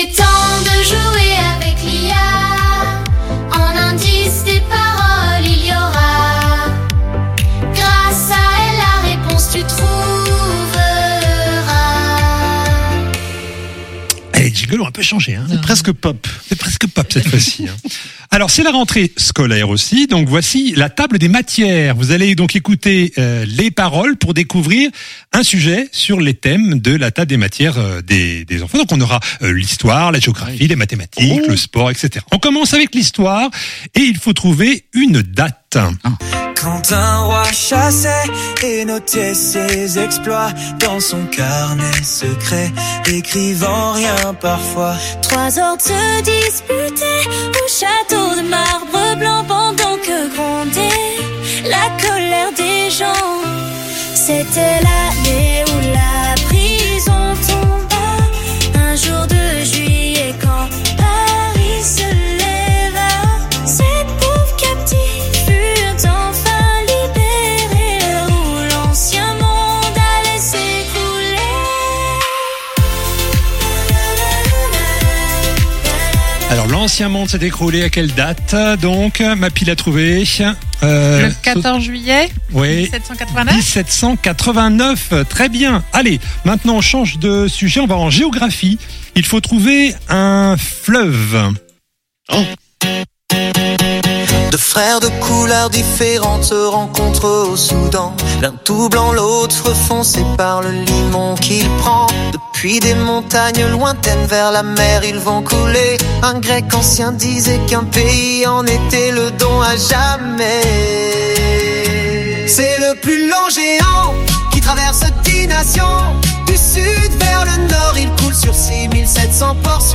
Jeux radio avec blind test en intelligence artificielle sur Radio G! Angers